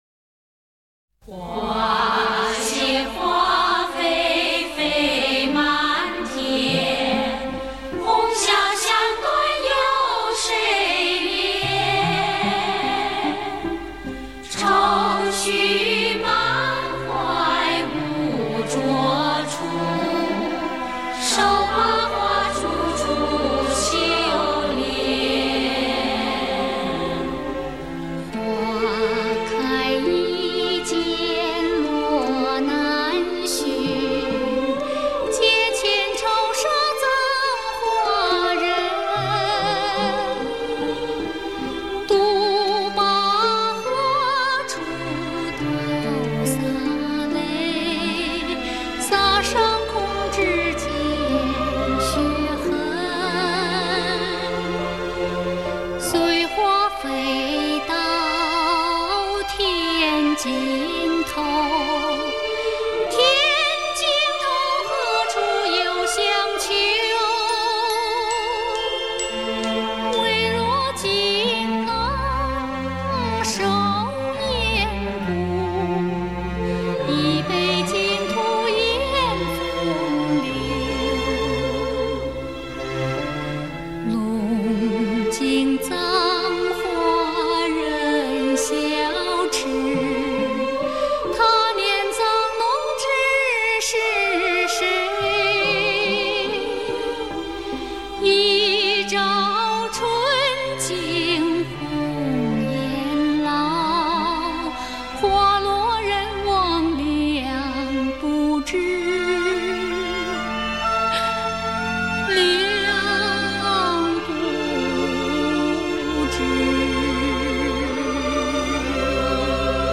通篇采用黄梅调唱腔，与央视版红楼梦的音乐比，丝毫不落下风。